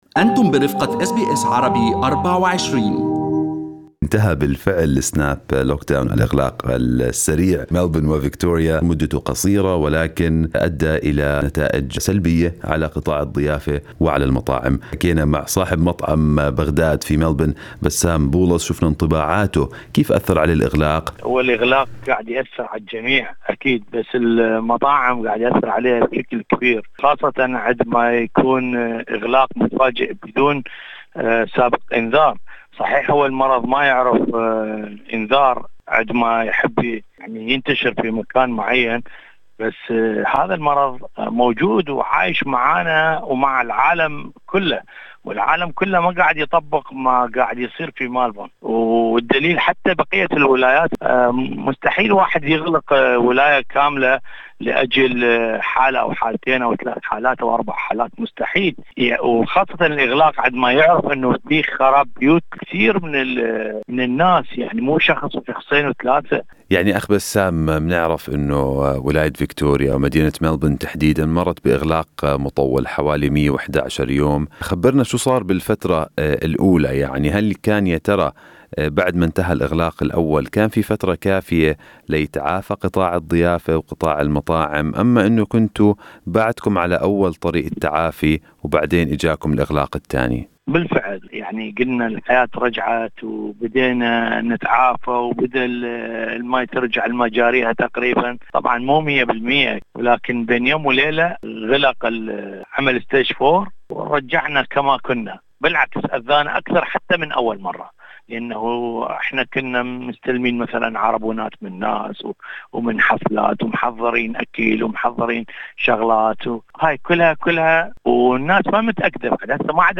وبنرة غاضبة